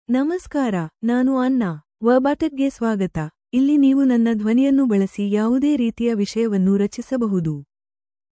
Anna — Female Kannada (India) AI Voice | TTS, Voice Cloning & Video | Verbatik AI
Anna is a female AI voice for Kannada (India).
Voice sample
Listen to Anna's female Kannada voice.
Anna delivers clear pronunciation with authentic India Kannada intonation, making your content sound professionally produced.